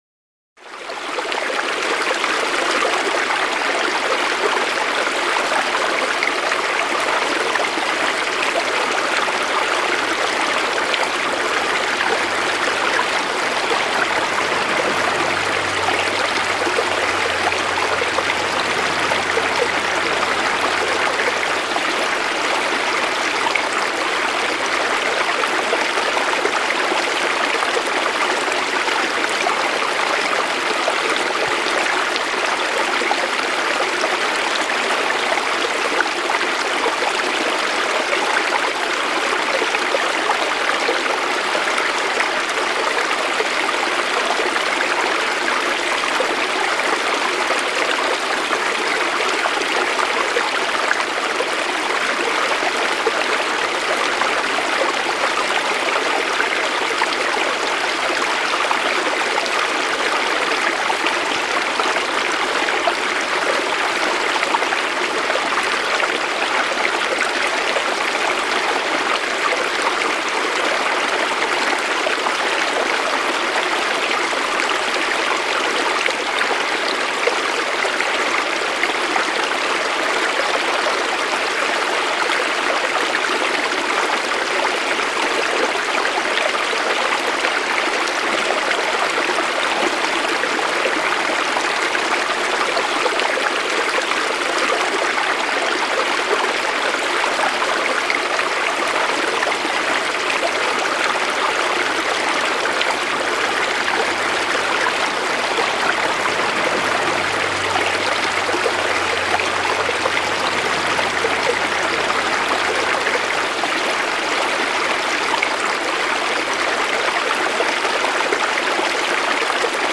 TIEFE ENTSPANNUNG: Fließwasser-Gelassenheit mit Wald-Quelle